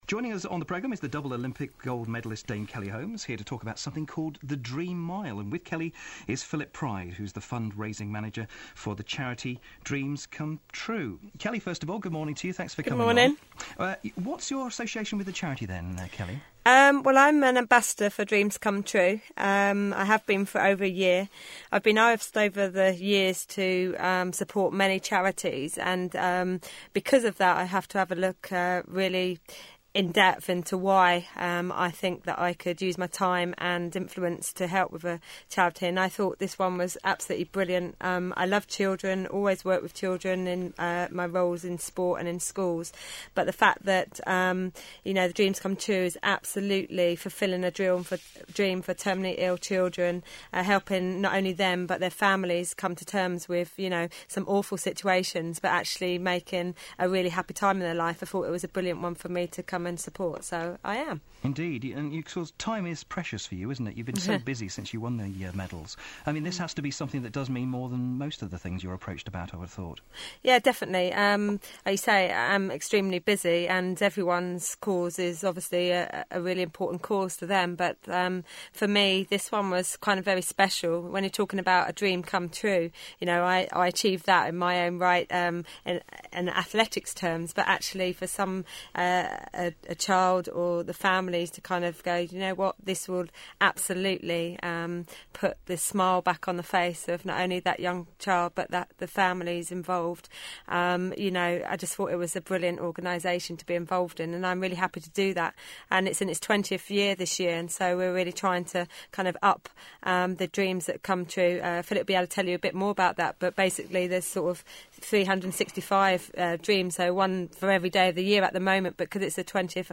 Kelly Holmes talks to BBC Gloucester about the Dreams Come True Foundation.